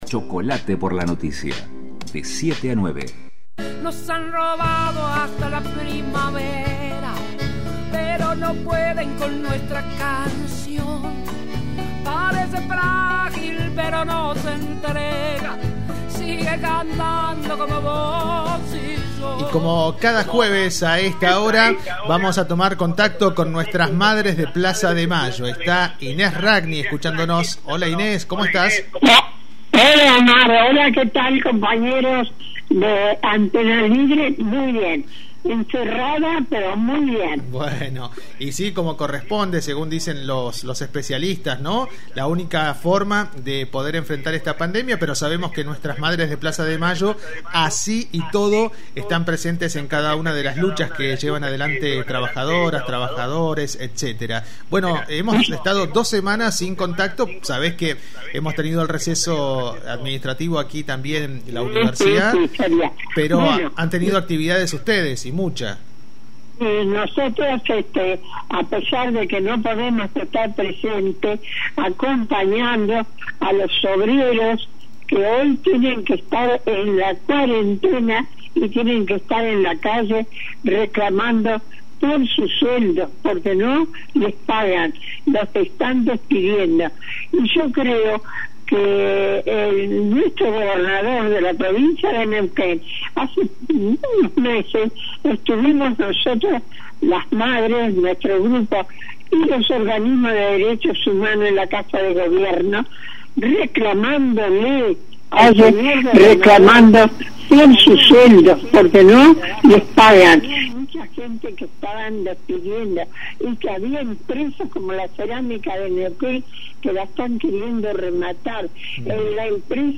Hoy en comunicación telefónica